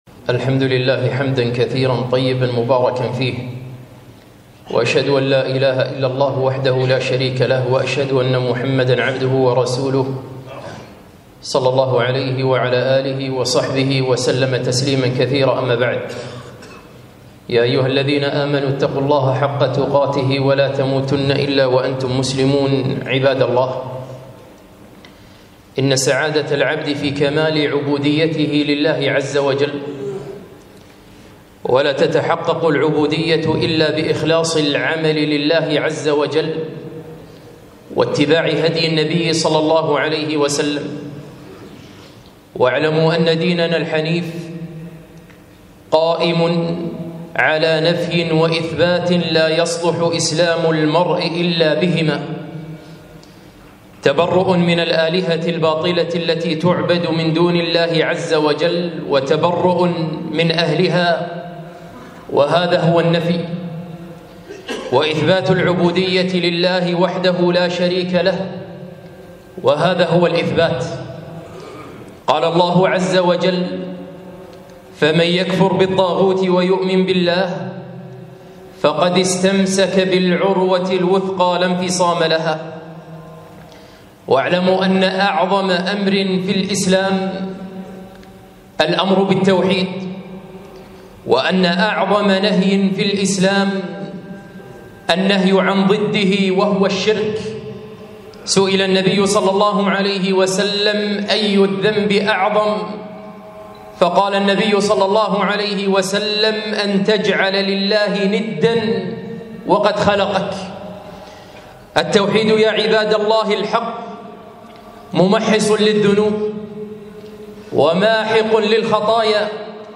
خطبة - توحيدك يا عبد الله